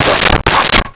Dog Barking 04
Dog Barking 04.wav